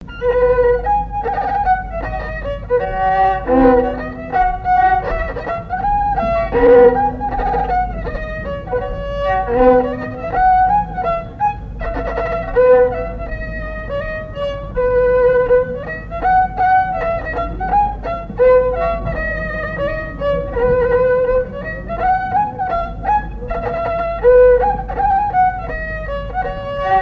Satiriques, plaisantes
danse : scottich trois pas
Assises du Folklore
Pièce musicale inédite